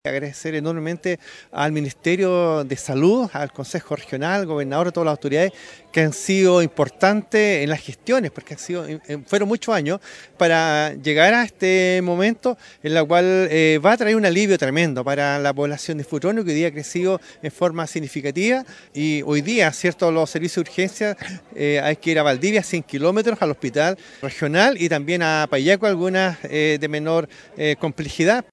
Alcalde-de-Futrono-Claudio-Lavado-primera-piedra-SAR-Futrono.mp3